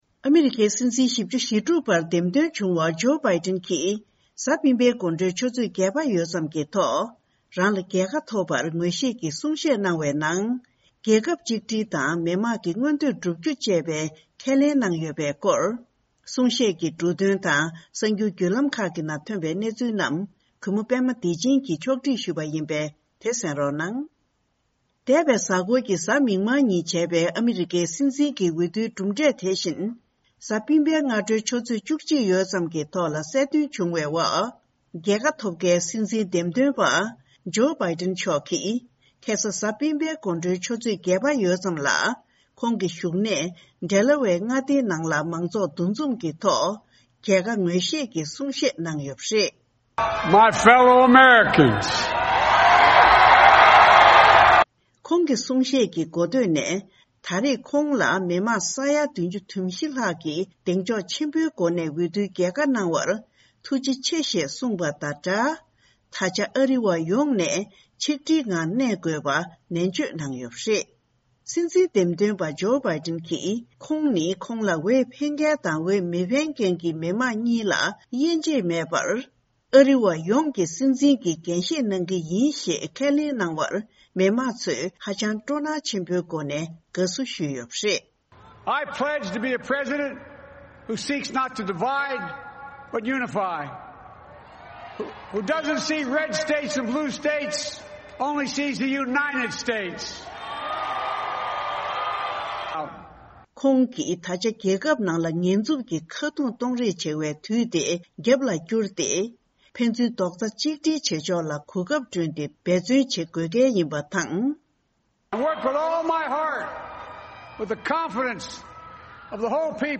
འདེམས་ཐོན་སྲིད་འཛིན་བྷ་ཌན་གྱིས་ཆིག་སྒྲིལ་དགོས་པའི་གསུང་བཤད་གནང་བ།